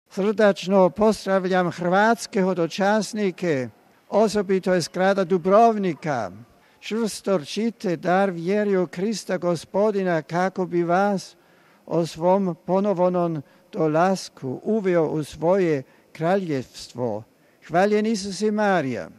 Hrvatske hodočasnike Papa je pozdravio ovim riječima: RealAudio